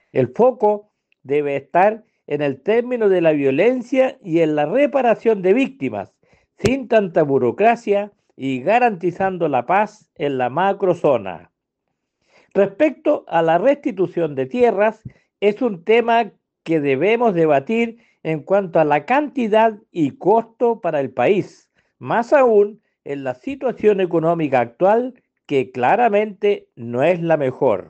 El diputado independiente ligado a Chile Vamos, Bernardo Berger, indicó que la reparación de las víctimas de violencia rural debe ejecutarse sin burocracia.